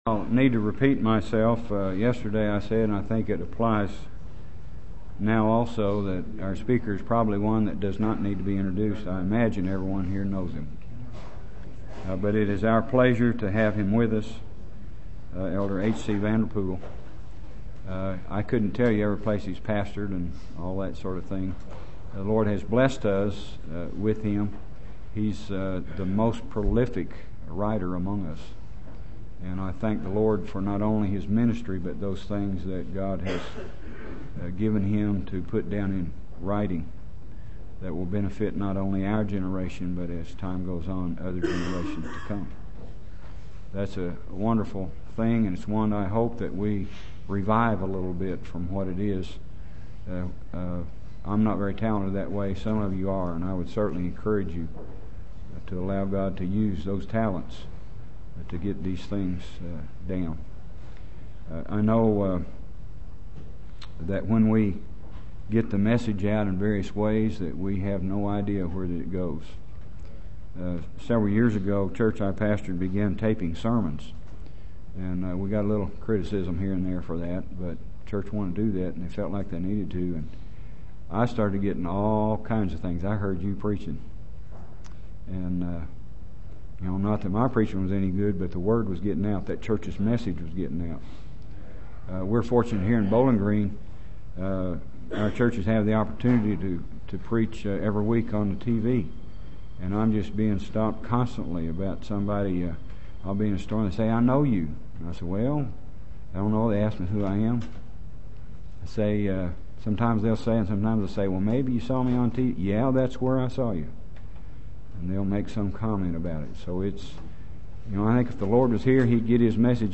Thursday night sermon from the 2005 Old Union Ministers School.